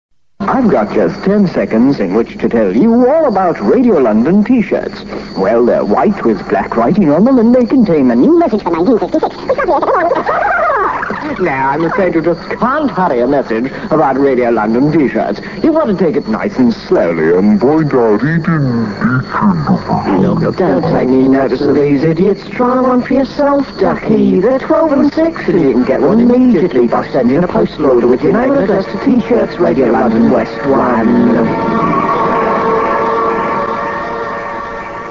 In the days before “varispeed” technology Kenny would often dismantle the equipment and go to enormous lengths to get the effects he was after: